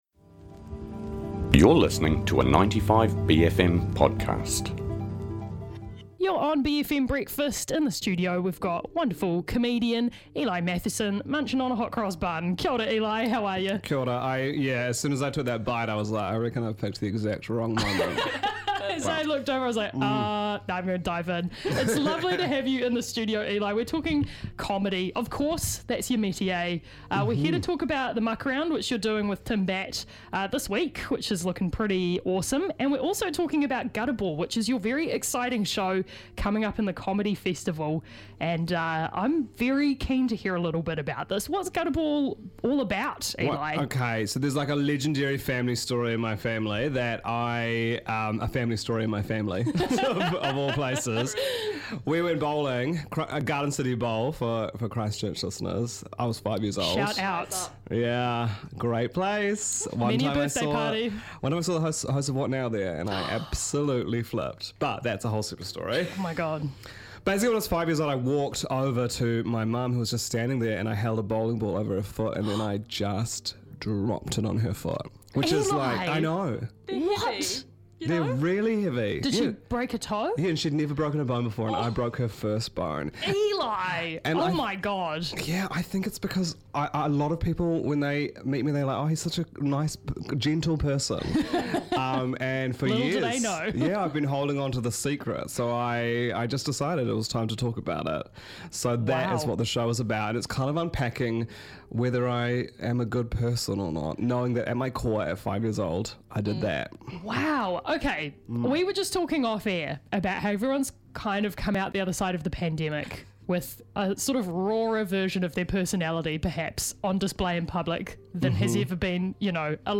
A simple feed of all the interviews from our many and varied special bFM Breakfast guests.